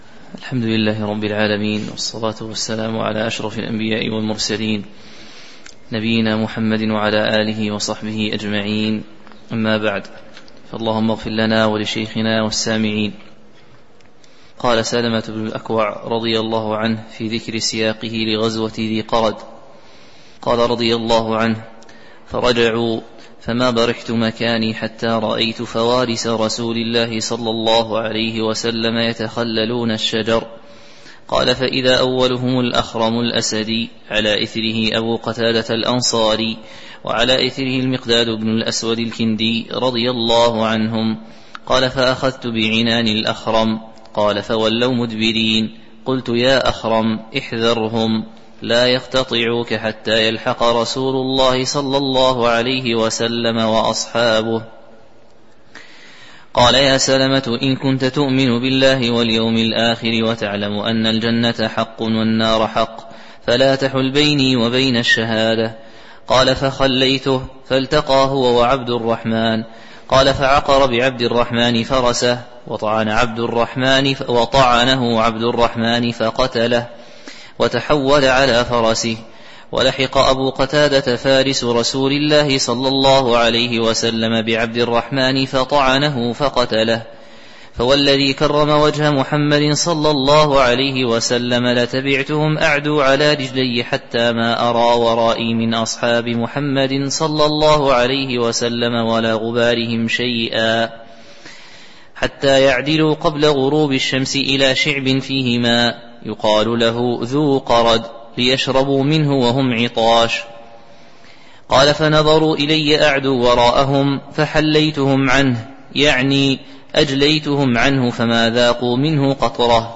تاريخ النشر ٩ جمادى الأولى ١٤٤٣ هـ المكان: المسجد النبوي الشيخ